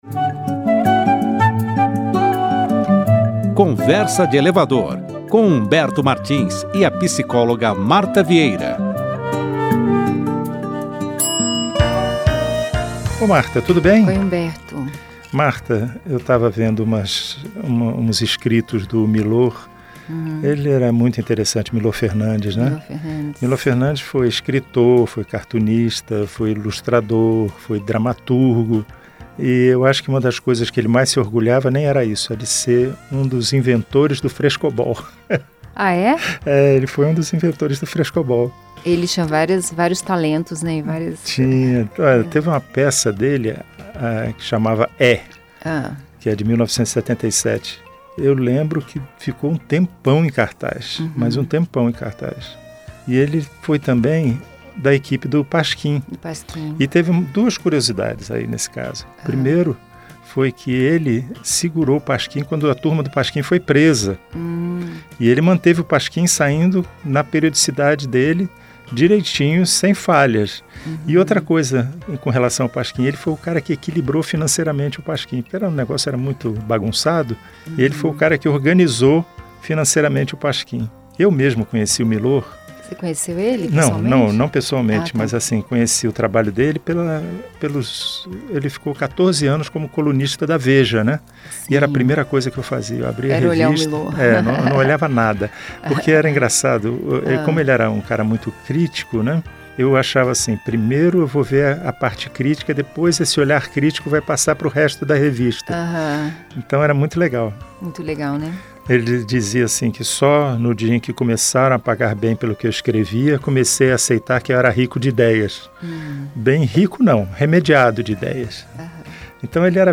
Um bate-papo leve e divertido sobre temas do cotidiano, mas com uma pitada de reflexão sobre psicologia, literatura e filosofia.